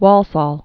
(wôlsôl, -səl)